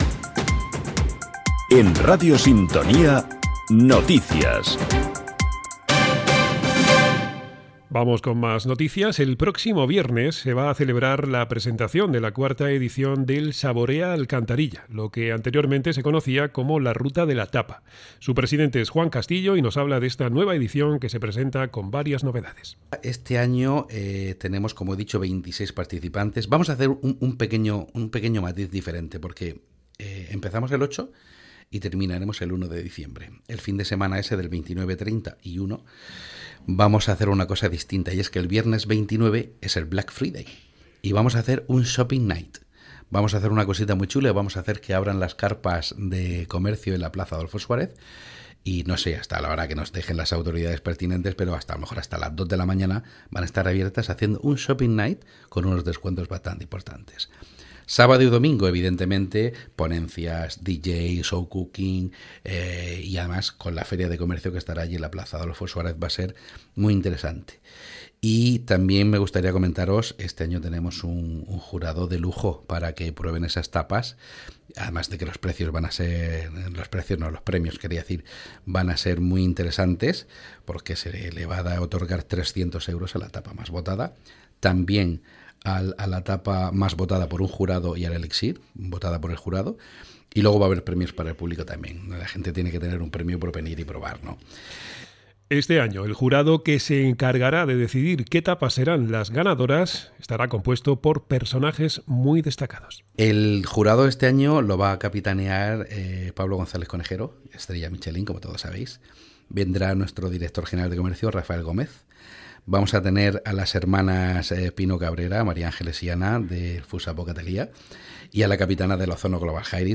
Audio de la noticia.